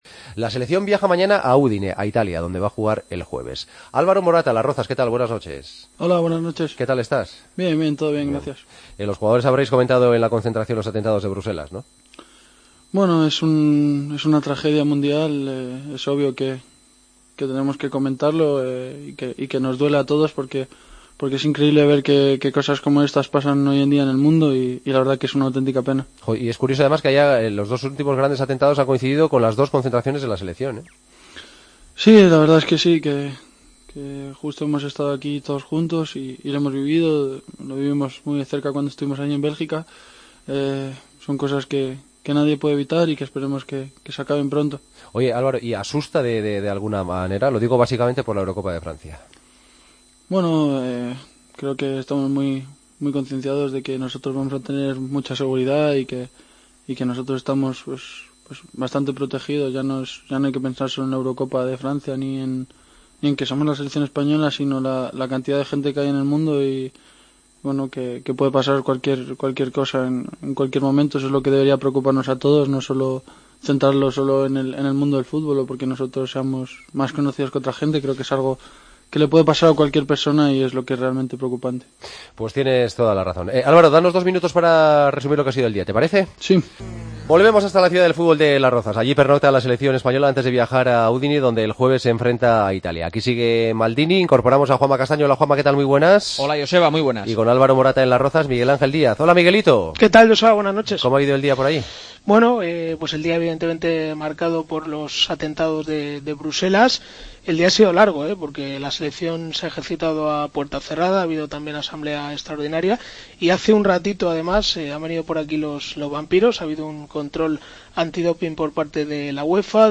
Entrevista a Morata en El Partido de las 12